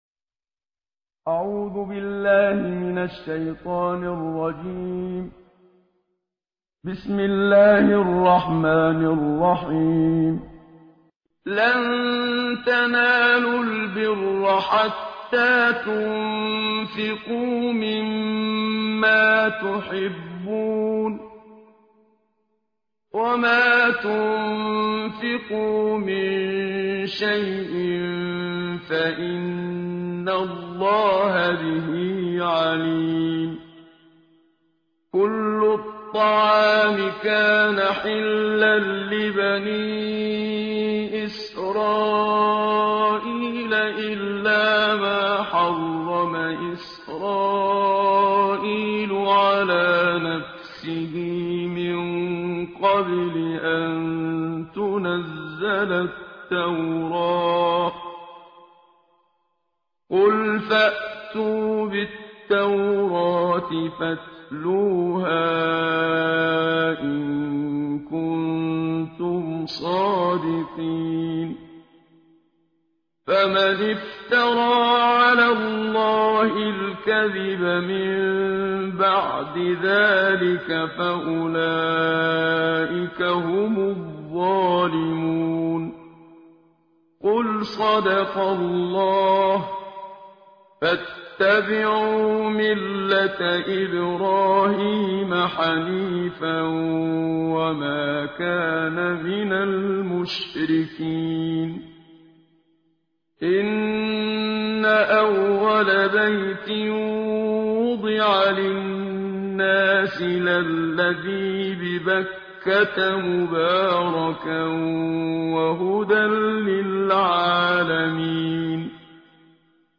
ترتیل جزء چهارم قرآن با صدای استاد منشاوی
تهران- الکوثر: در چهارمین روز ماه مبارک رمضان، ترتیل جزء چهارم قرآن کریم با صدای استاد منشاوی قاری بزرگ مصری تقدیم شما بزرگواران می شود.